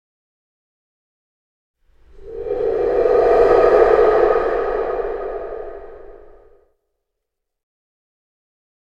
sfx_wind_hole.mp3